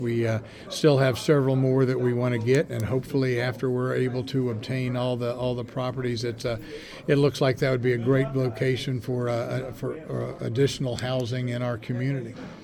The Cumberland Mayor and City Council motioned to acquire 403-405 and 417 Central Avenue during Monday’s City Council public meeting.  Mayor Ray Morris says the properties could be a good place for additional housing…